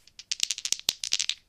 dieShuffle1.ogg